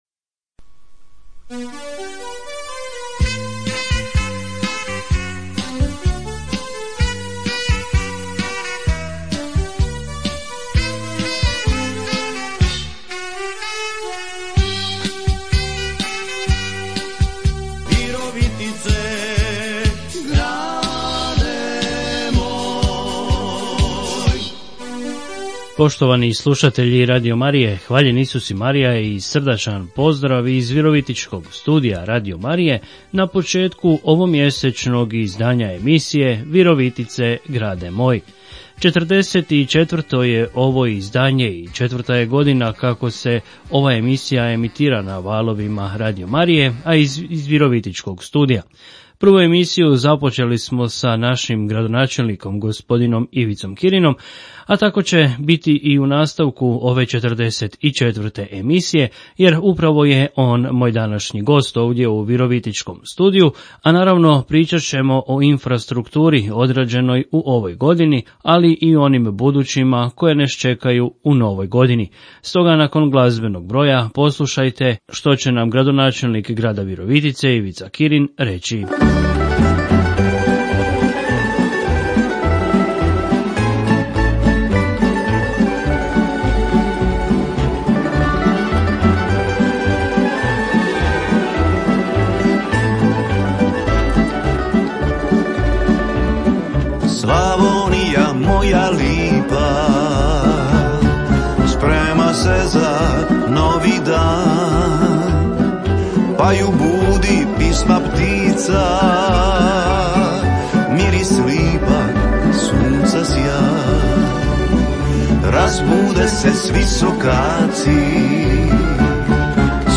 Radio Marija Hrvatska - Virovitice, grade moj! - gost: Ivica Kirin, gradonačelnik Grada Virovitice